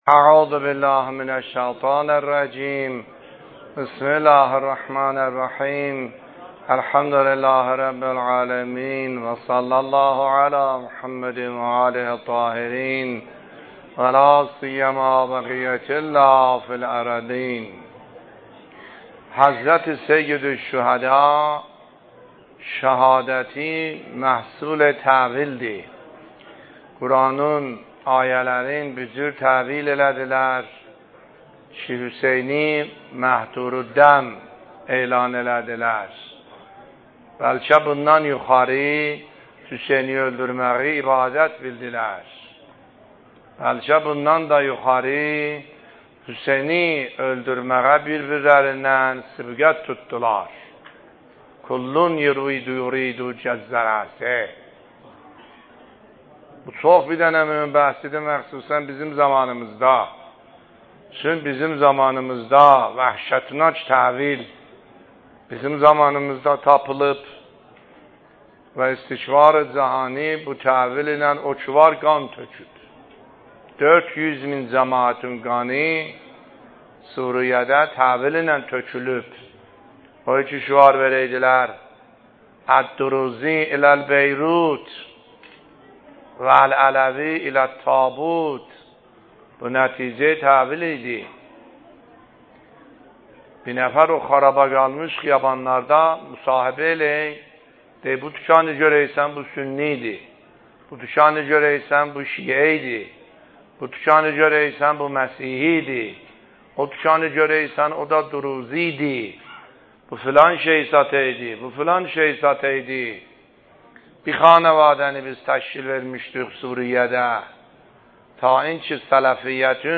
سخنرانی آیه الله سیدحسن عاملی فایل شماره ۷ - دهه اول محرم ۱۳۹۷